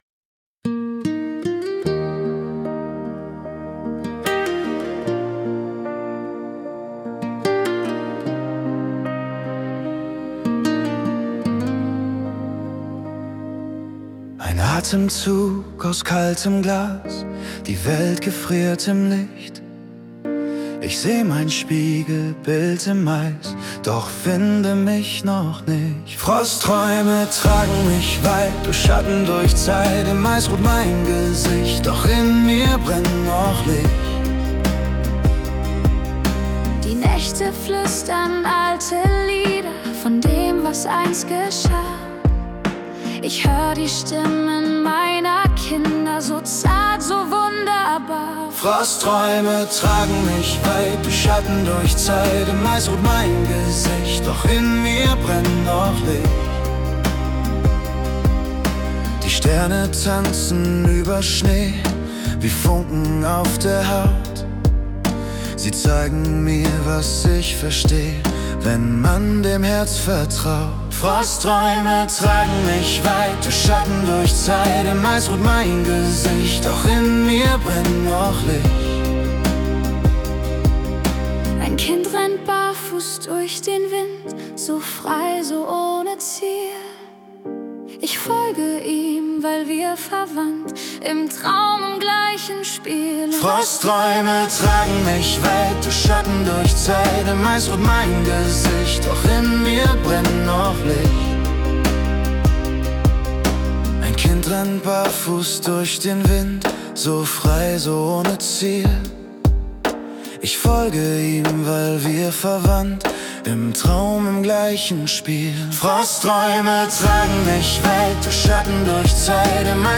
→ Kühle Klänge und weiche Melancholie.